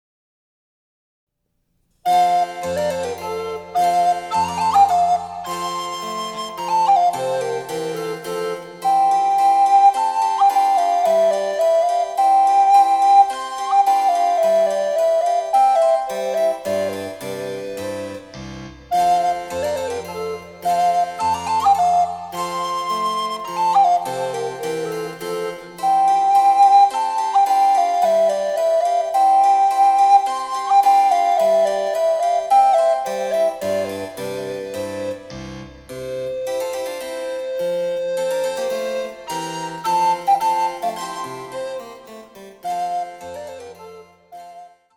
★クラシックの名作３曲をアルトリコーダーで演奏できる「チェンバロ伴奏ＣＤブック」です。
・各曲につきテンポの異なる３種類のチェンバロ伴奏
・リコーダー演奏例